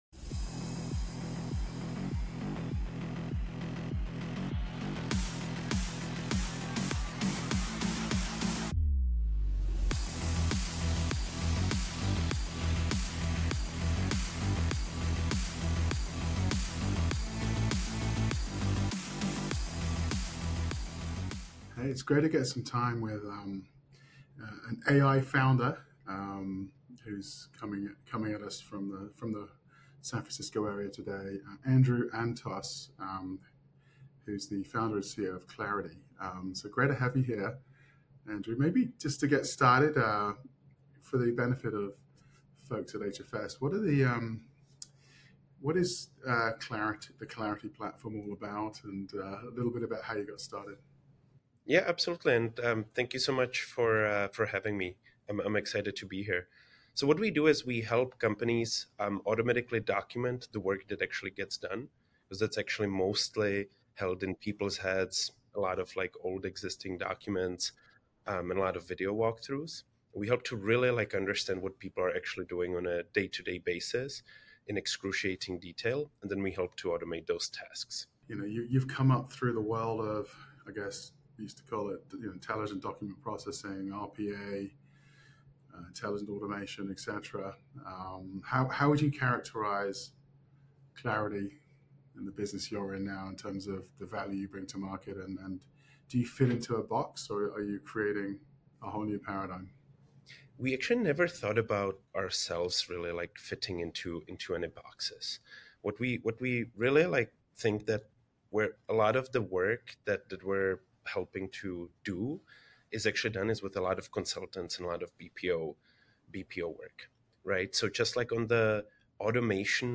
Fireside Chat | HFS Research and Klarity discuss work process automation